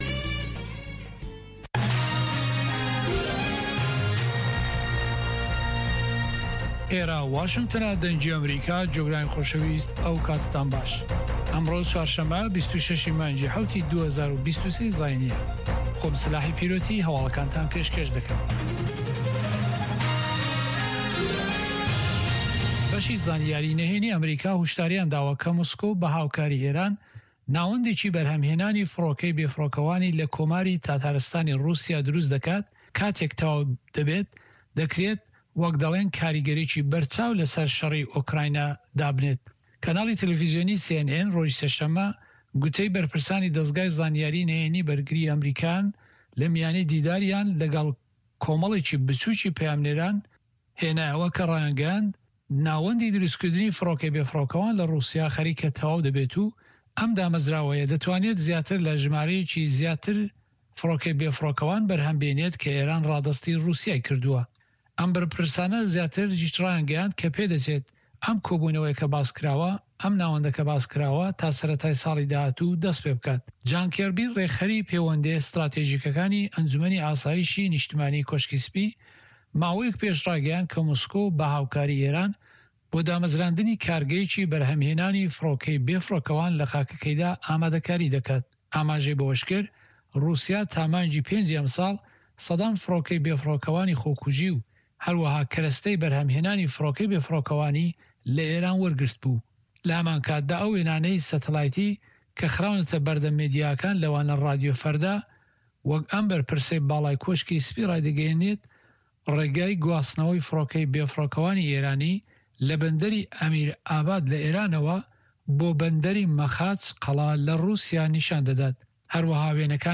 Nûçeyên Cîhanê ji Dengê Amerîka